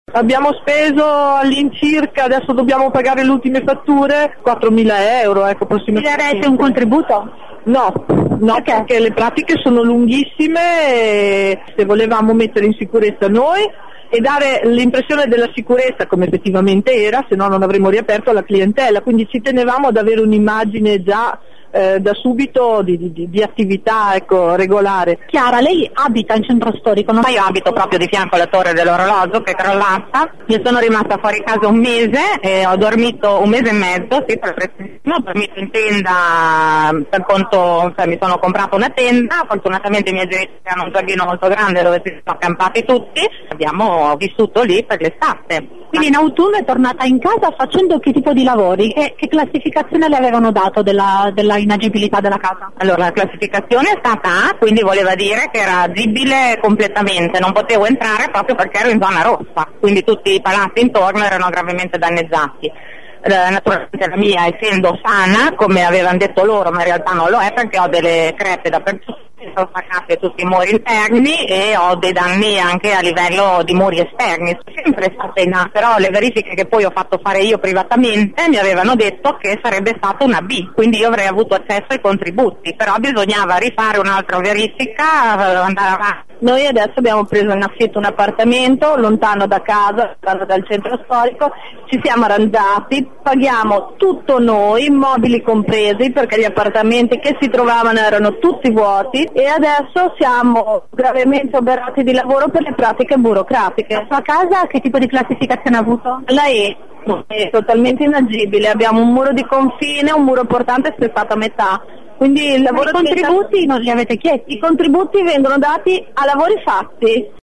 Questa mattina al presidio hanno partecipato soprattutto commercianti di Finale, alcuni dei quali hanno avuto anche la casa inagibile.
voci-finalesi-sito.mp3